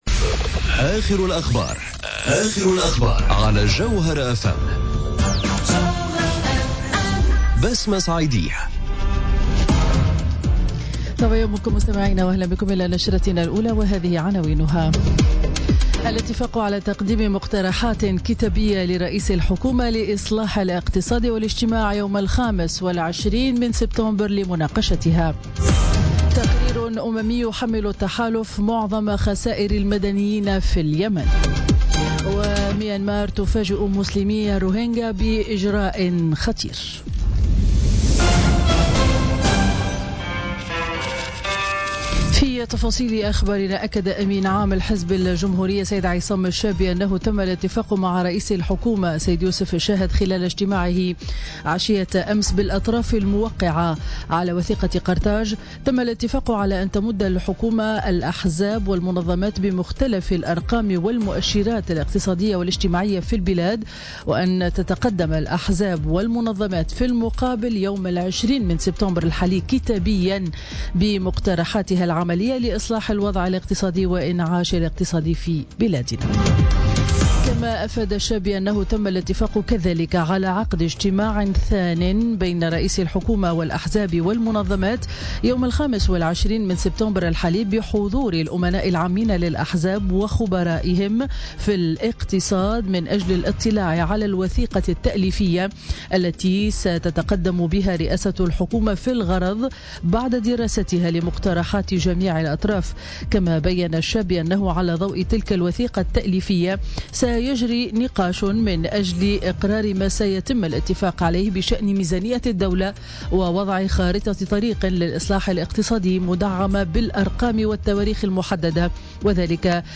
نشرة أخبار السابعة صباحا ليوم الاربعاء 6 سبتمبر 2017